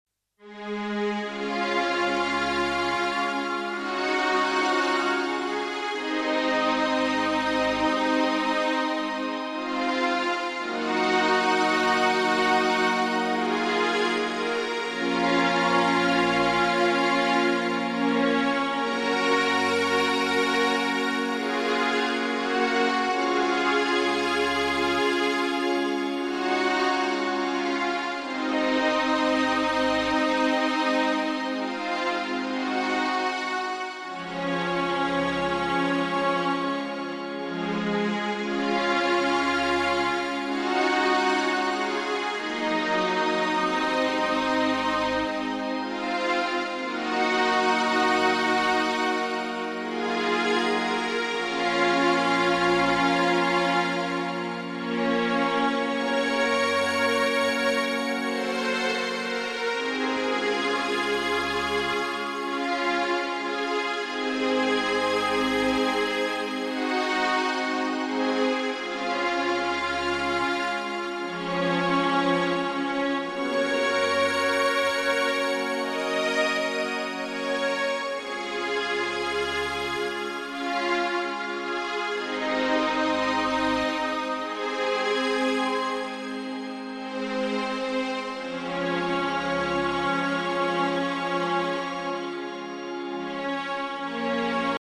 Musique new age: